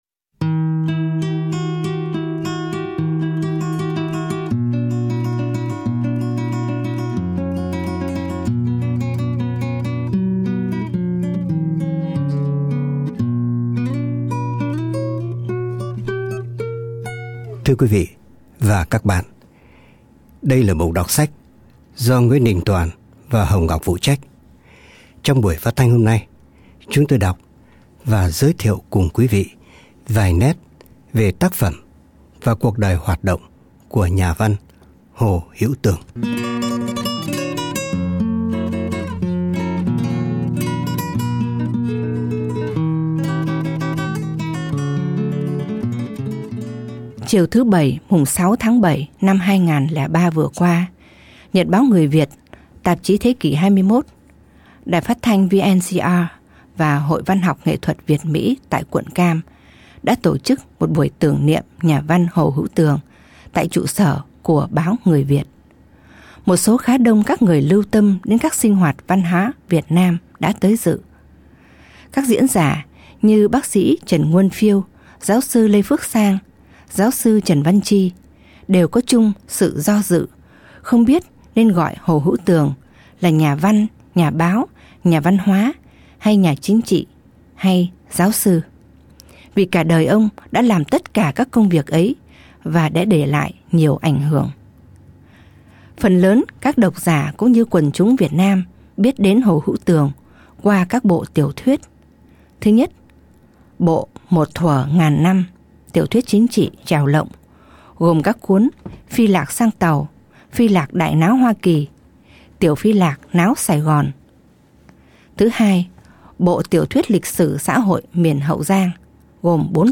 Đọc Sách